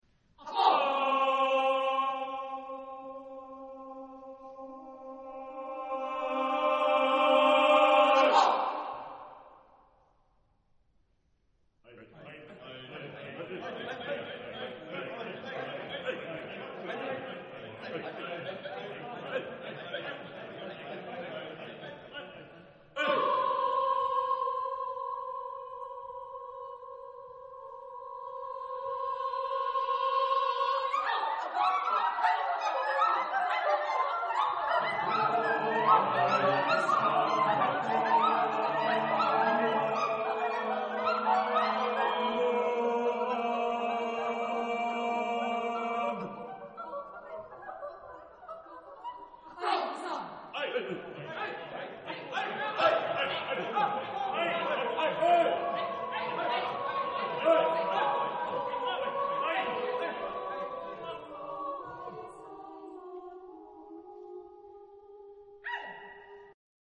Genre-Style-Forme : Sacré
Type de choeur : 4S-4A-4T-4B  (16 voix mixtes )